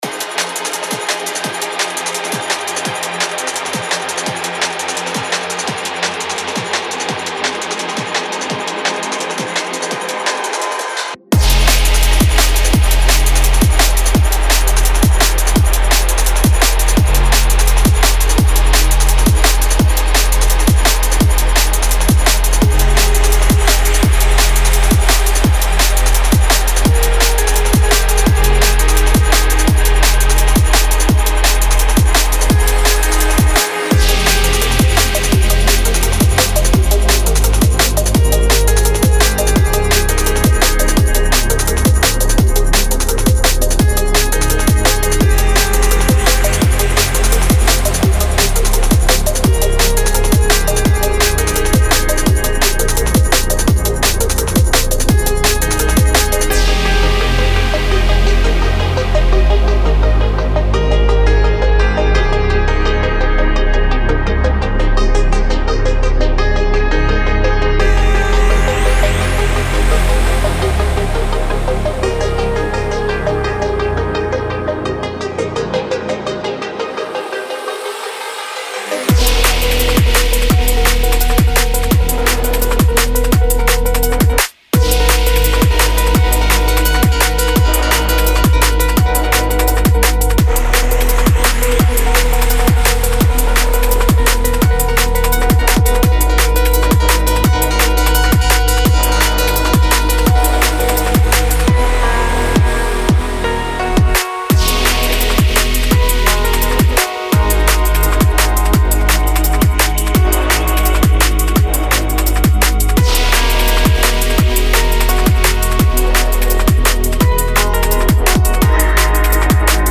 Genre:Jungle
これは呼吸し、揺らぎ、催眠的に惹き込むジャングルであり、リズムだけでなくムードや感情によってもリスナーを引き込みます。
10 Piano Loops
10 Synth Pluck Loops